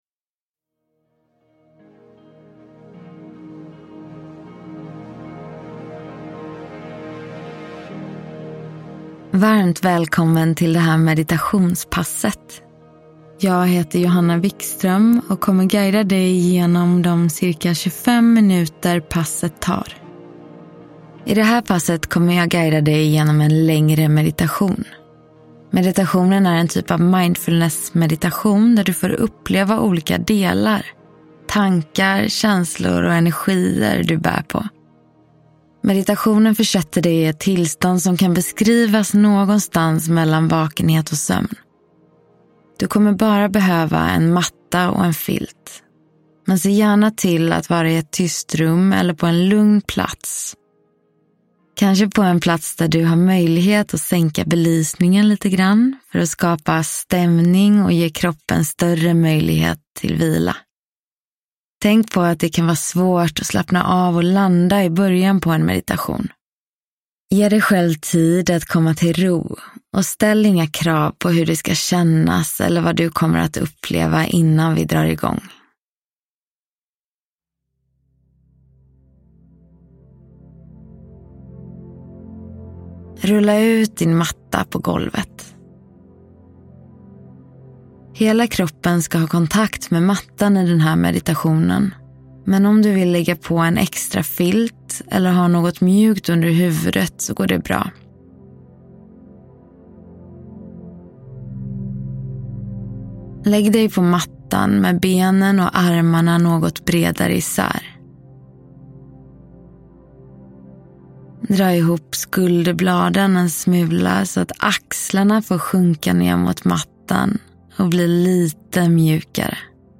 Yinyoga - Meditation (ljudbok